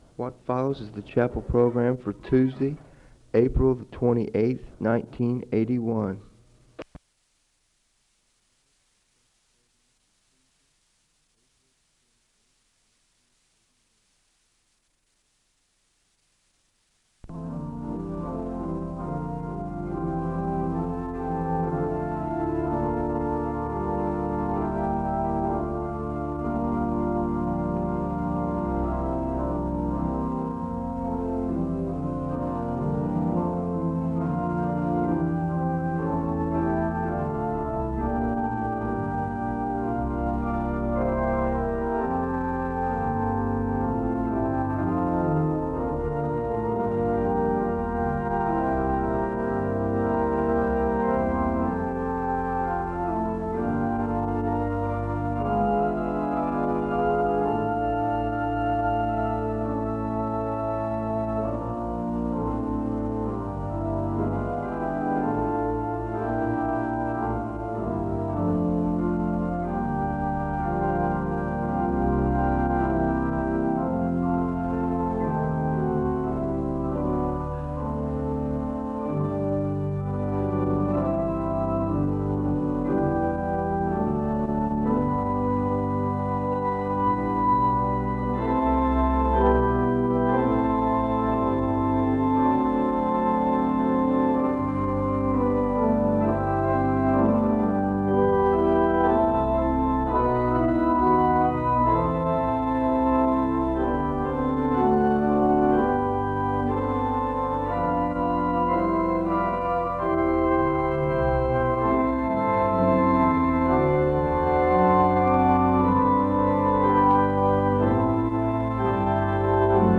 The service begins with organ music (00:00-06:32).
The choir sings a song of worship (13:19-16:20).